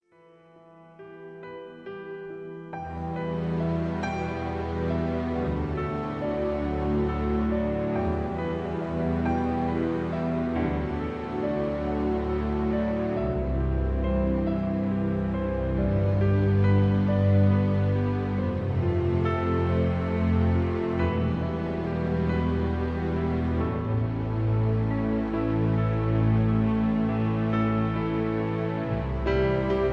(Key-Em, Tono de Em) Karaoke MP3 Backing Tracks